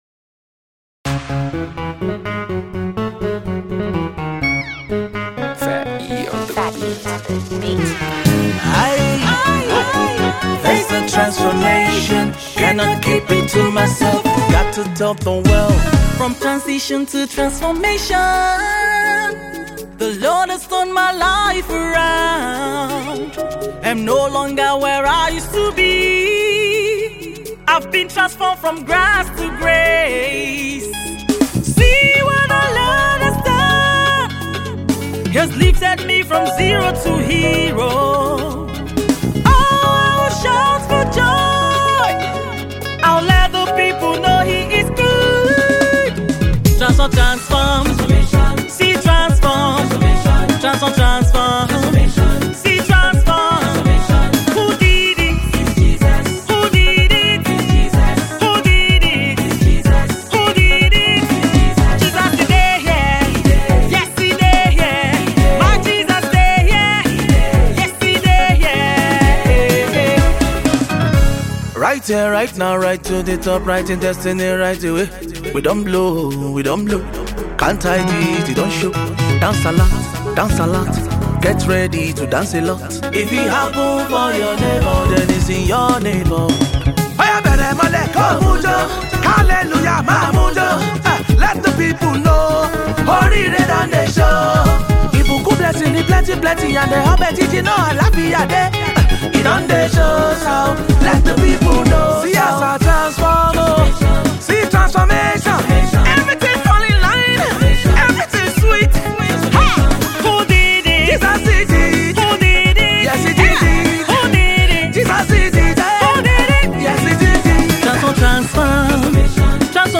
uptempo tune